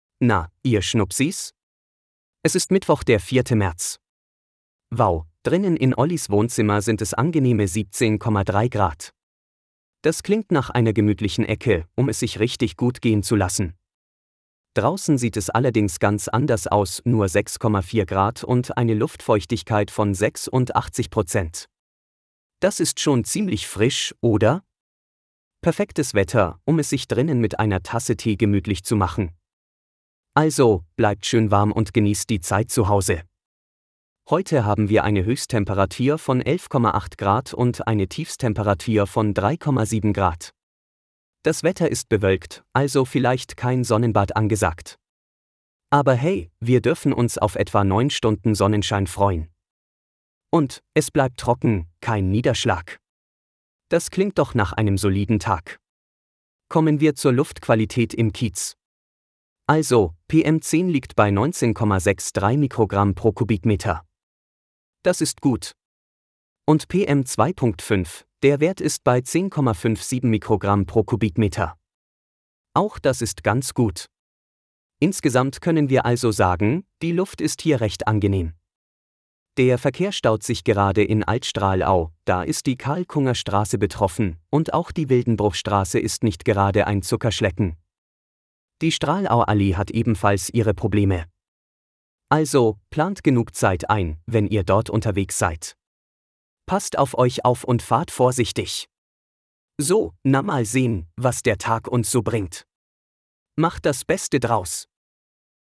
Automatisierter Podcast mit aktuellen Wetter-, Verkehrs- und Geburtstagsinfos.